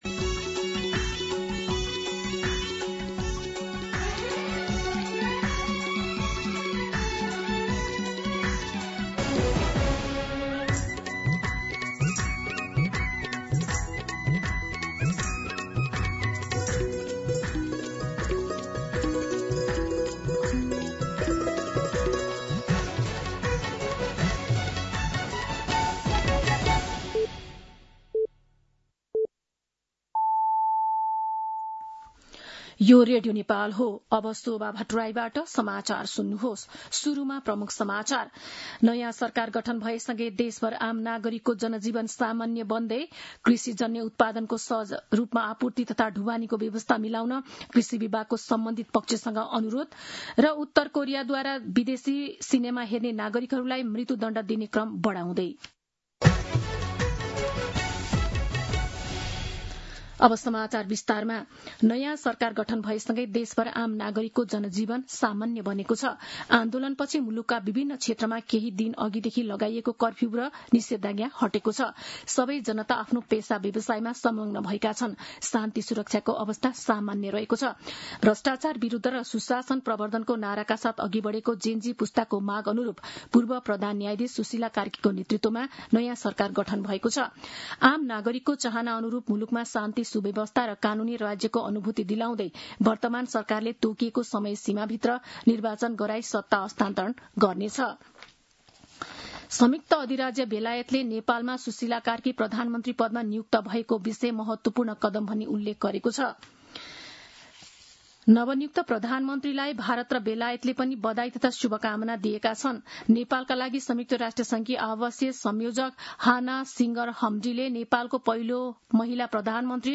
दिउँसो ३ बजेको नेपाली समाचार : २८ भदौ , २०८२
3-pm-Nepali-News.mp3